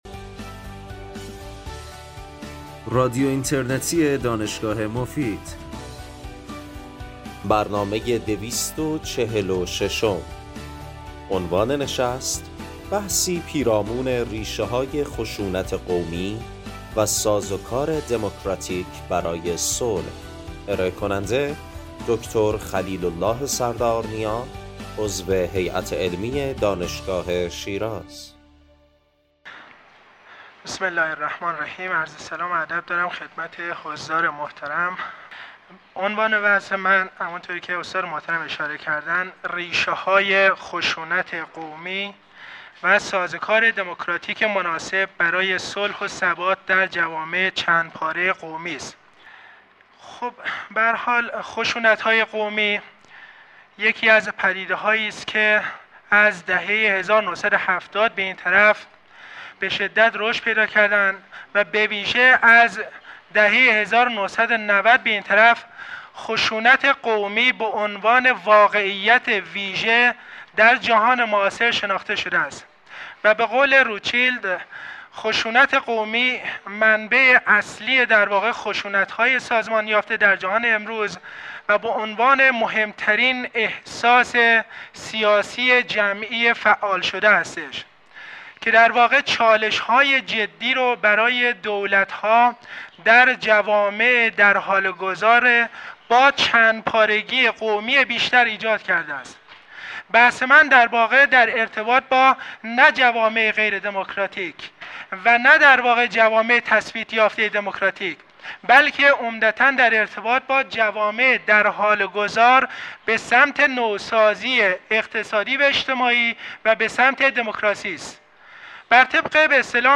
این سخنرانی در سال ۱۳۸۸ و در پنجمین همایش بین المللی حقوق بشر ارائه شده است.
بخش پایانی برنامه به پرسش و پاسخ اختصاص دارد.